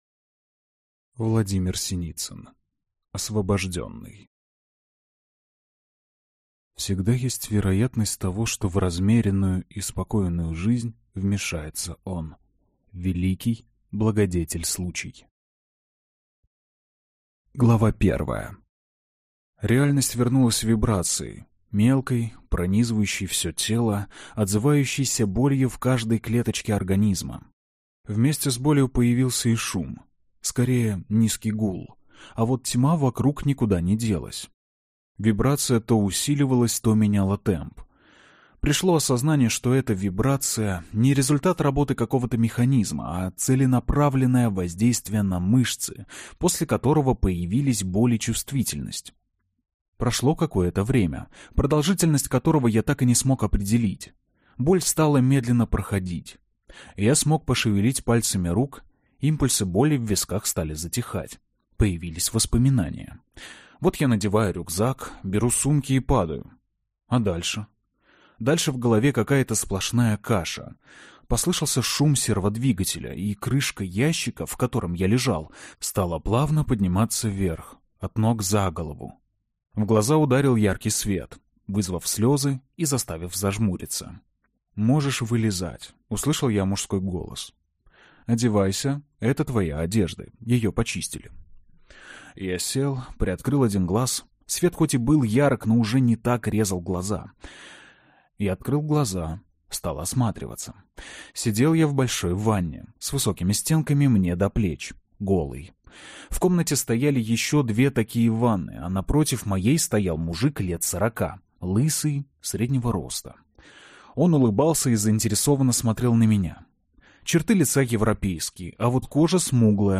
Аудиокнига Освобожденный | Библиотека аудиокниг